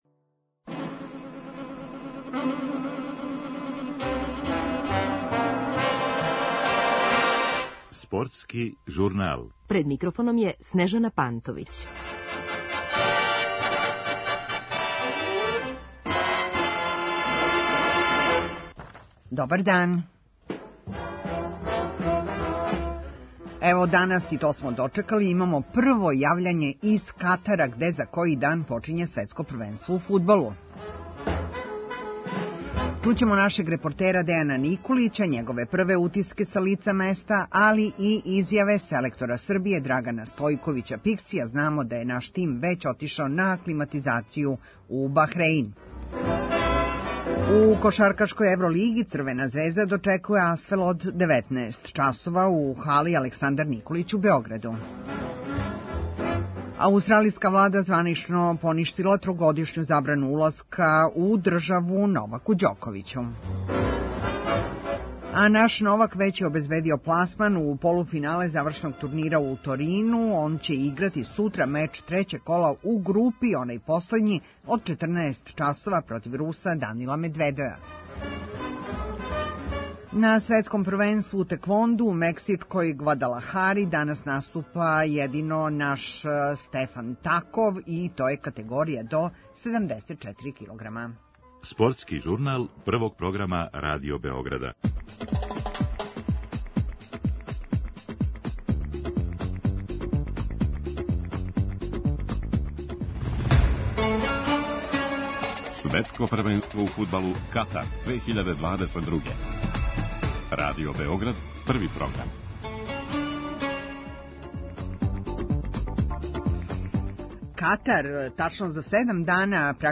Данас у Спортском журналу имамо прво јављање из Катара где за који дан почиње Светско првенство у фудбалу.